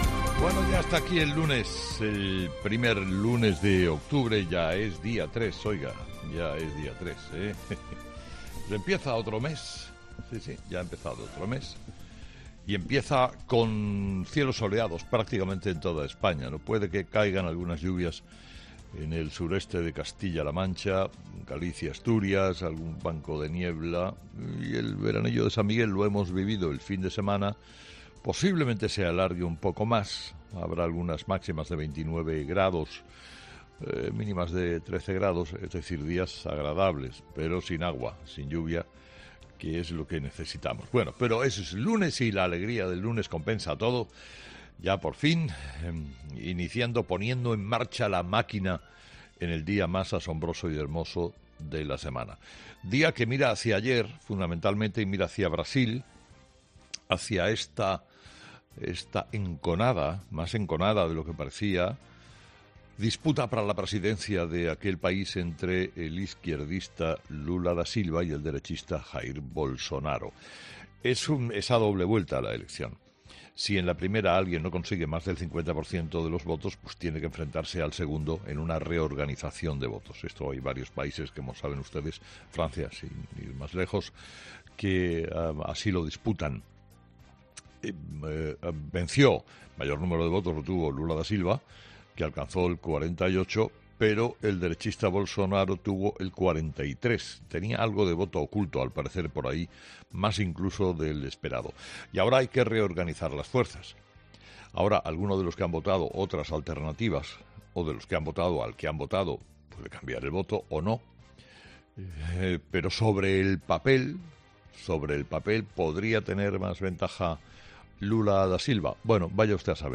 Carlos Herrera, director y presentador de 'Herrera en COPE', ha comenzado el programa de este lunes analizando las principales claves de la jornada, que pasan, entre otros asuntos, por la resaca de las elecciones en Brasil y por la situación del Govern en Cataluña.